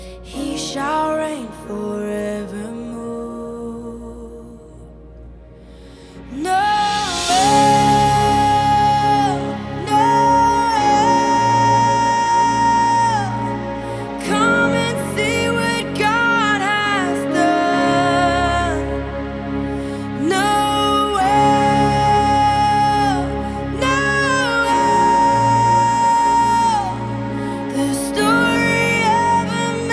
• Christian & Gospel